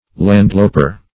Landloper \Land"lo`per\, n.